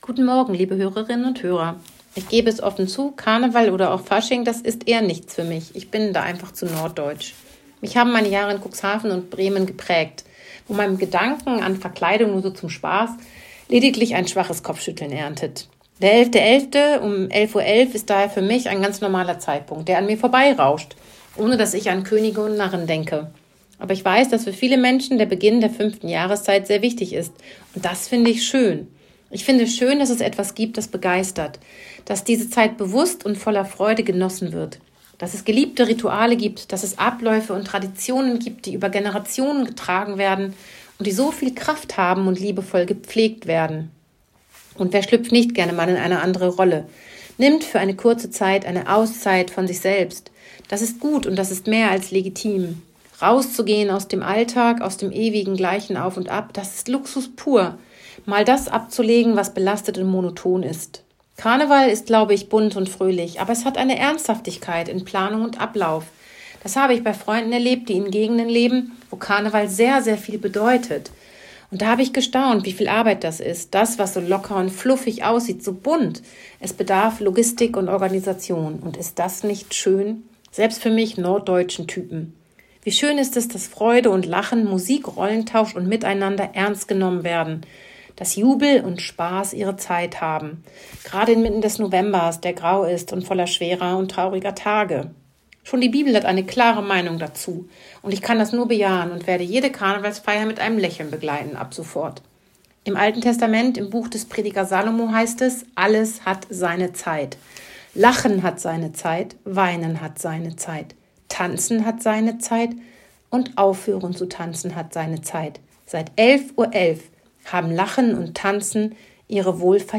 Radioandacht vom 11. November